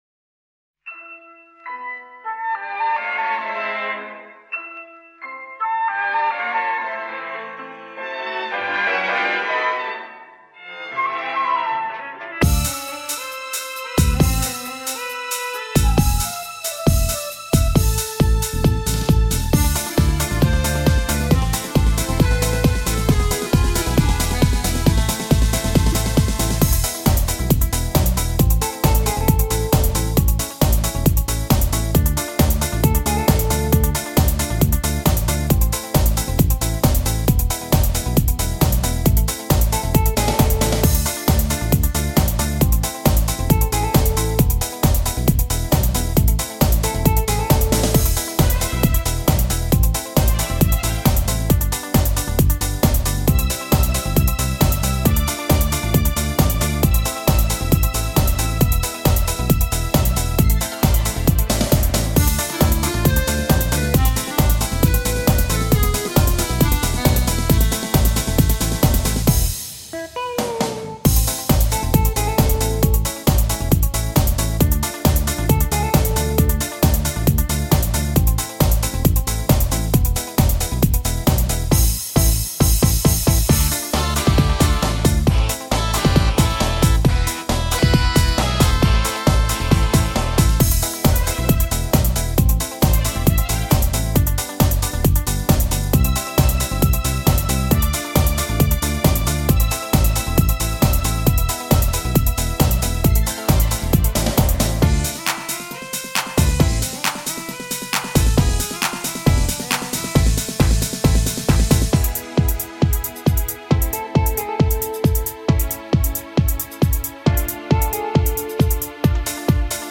Здесь вы можете бесплатно скачать минусовку